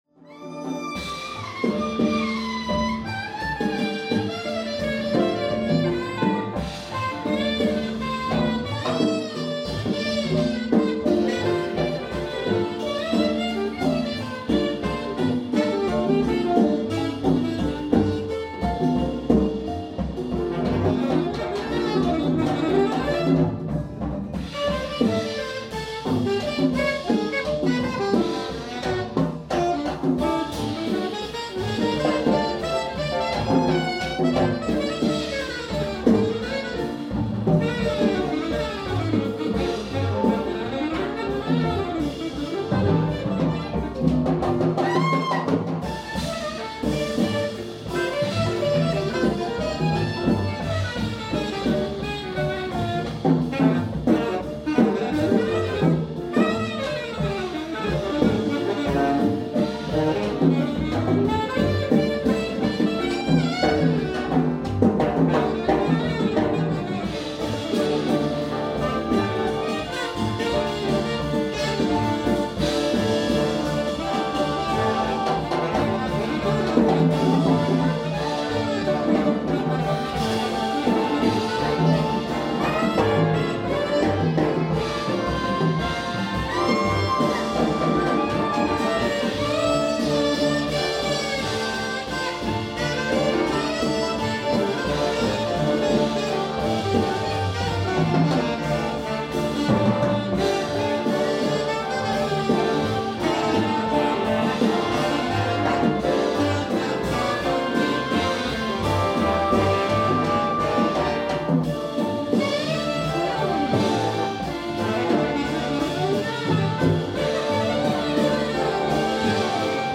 aus der jährlichen Konzertreihe